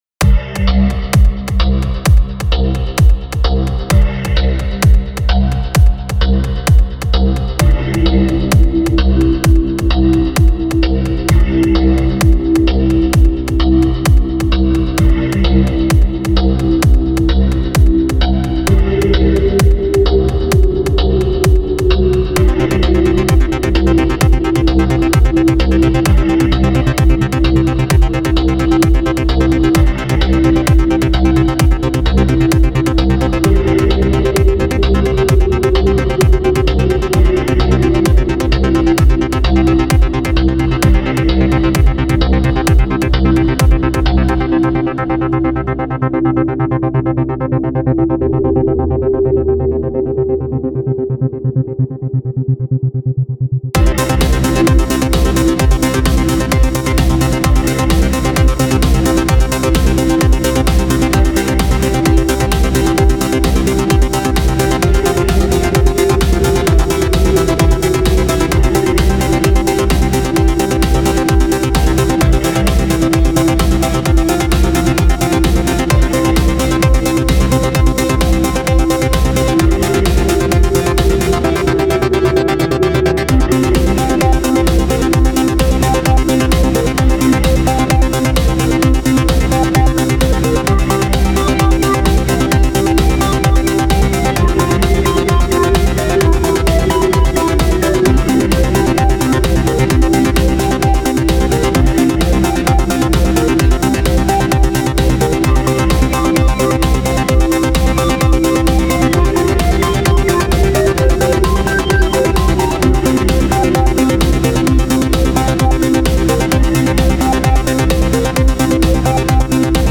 Such a ravin' tune.